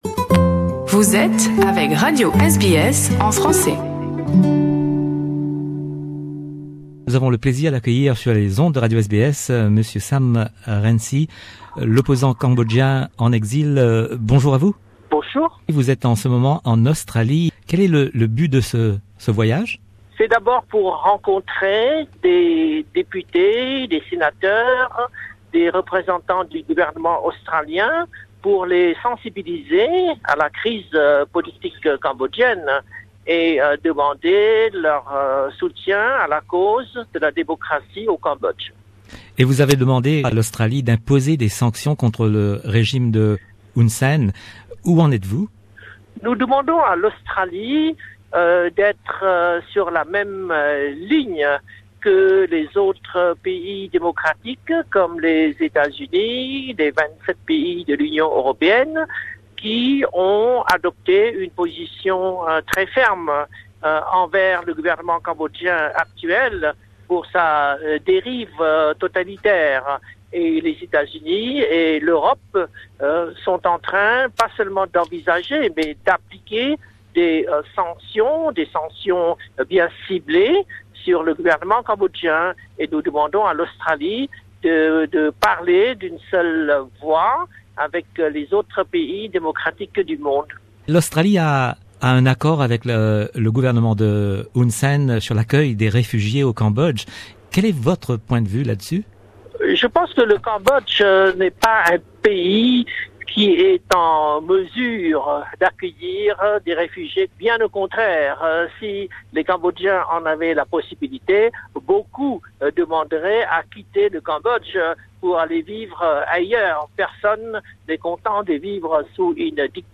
Interview avec Sam Rainsy, l'opposant cambodgien en exil, de passage en Australie en ce mois de février 2018.